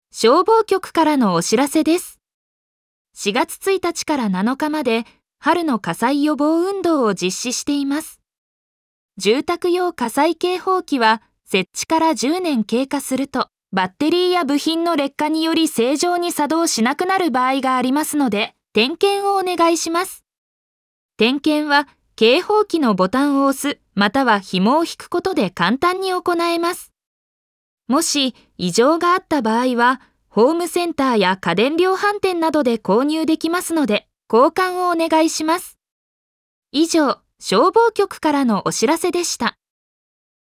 本音声データは、当運動期間中の館内放送のための音声データとなります。